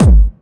GS Phat Kicks 002.wav